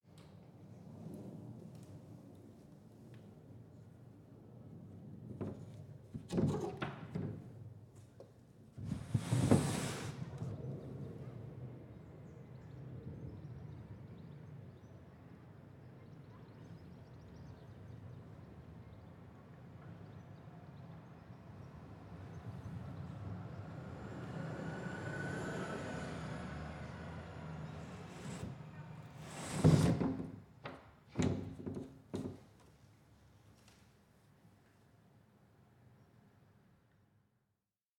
Ambient recording 3
A recording featuring the sounds of an old casement window being opened onto a busy main street, so that the sounds from outdoors reverberate in the acoustics of the room inside.